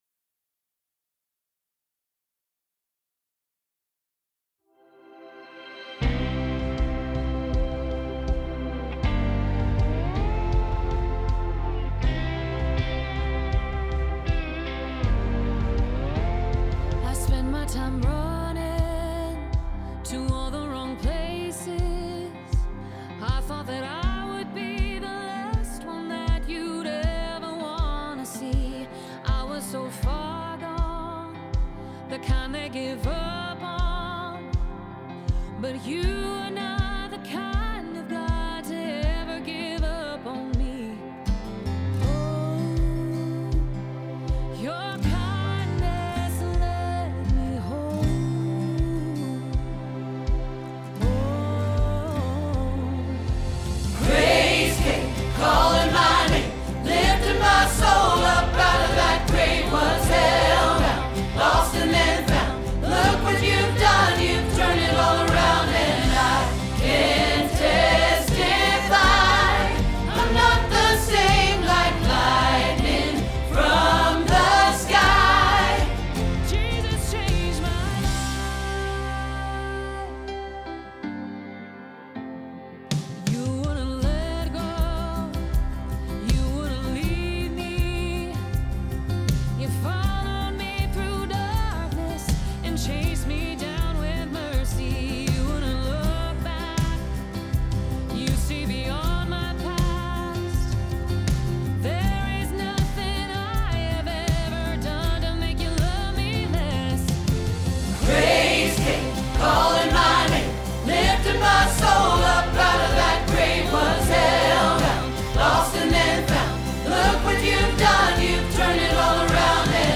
Jesus Changed My Life – Alto – Hilltop Choir